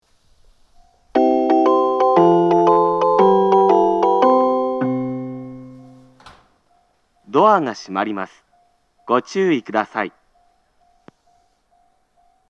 発車メロディー
こちらも一度扱えばフルコーラス鳴ります。